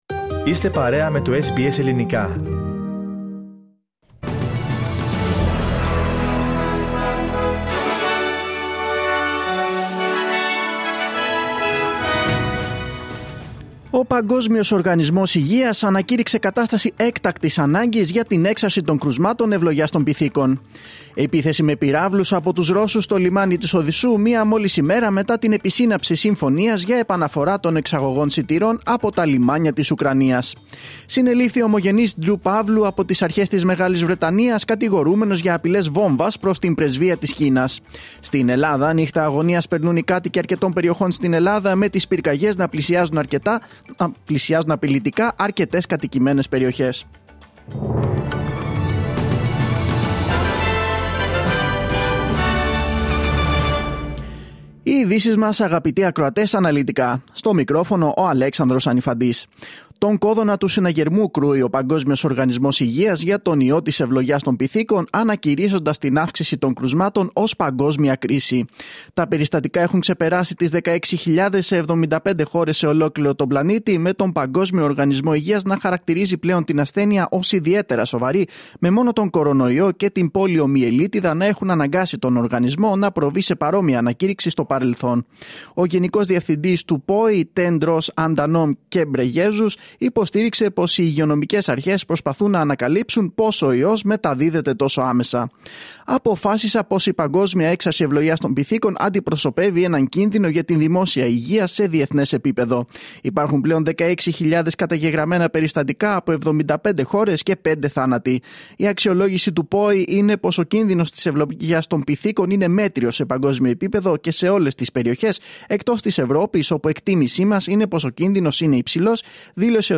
Δελτίο Ειδήσεων Κυριακή 24.7.2022
News in Greek.